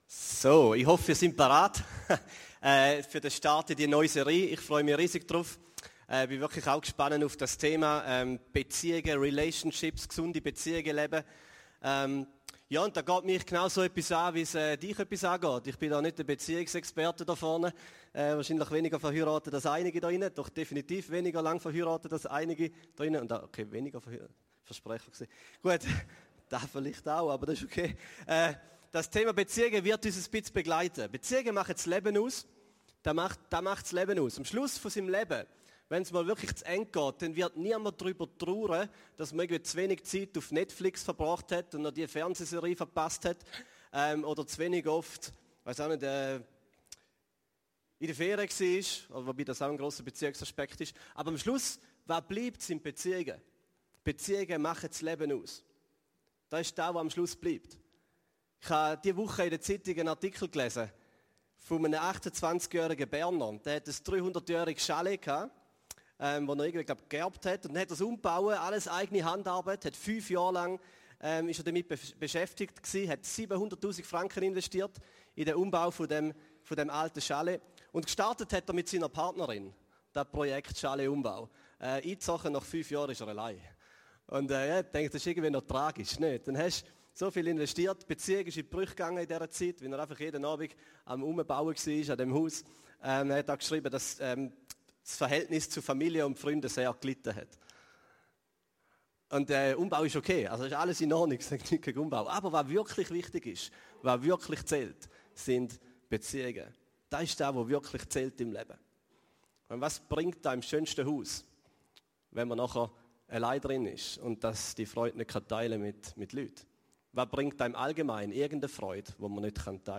REALationships – Predigtserie